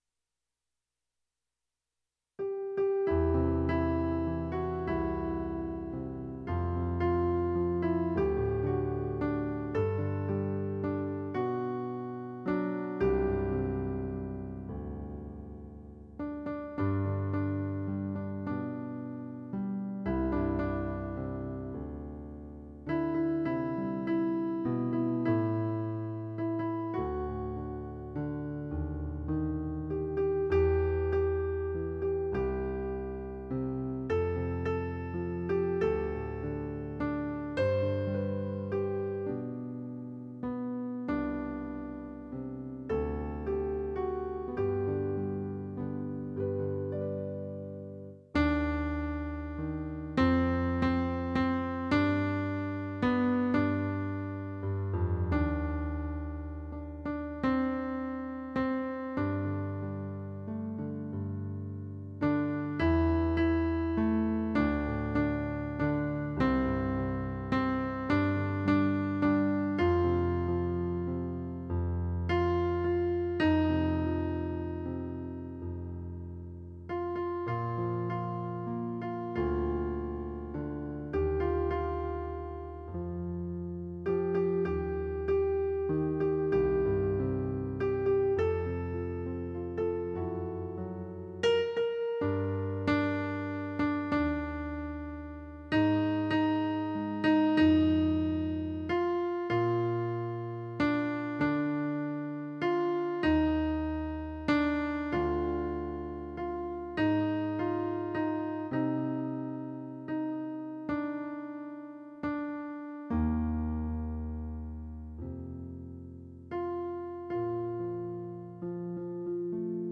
Piano & Voice